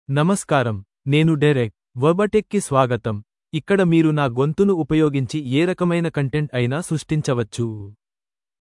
Derek — Male Telugu AI voice
Derek is a male AI voice for Telugu (India).
Voice sample
Listen to Derek's male Telugu voice.
Male
Derek delivers clear pronunciation with authentic India Telugu intonation, making your content sound professionally produced.